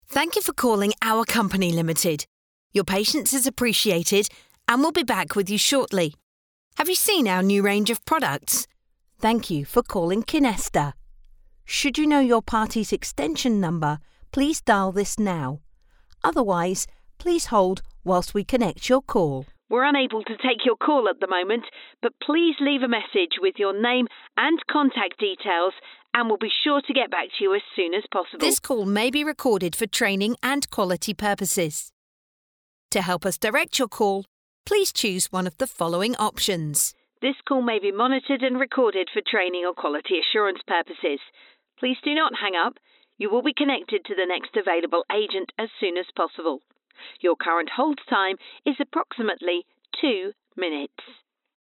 Englisch (Britisch)
IVR
Hoch